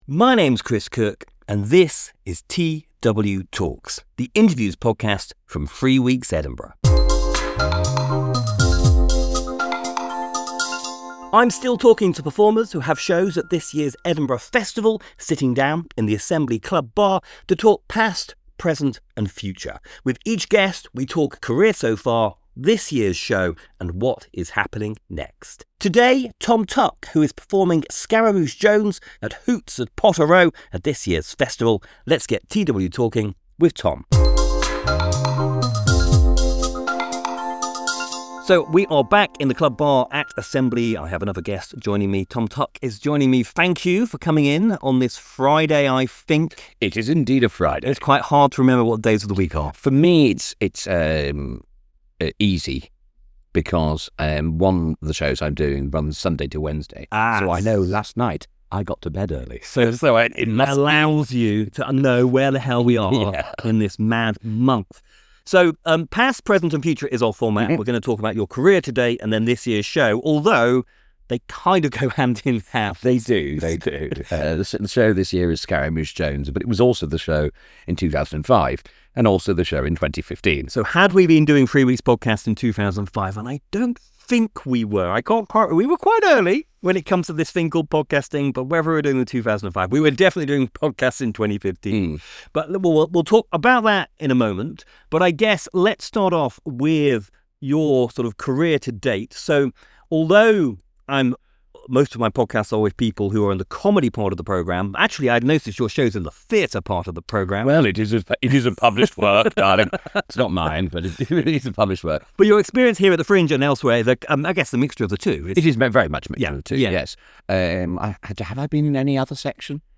TW:Talks is an interviews podcast